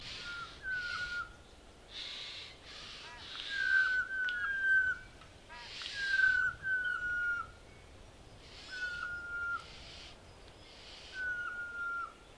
To hear calls of the choughs, click on the sound icons below:
Adults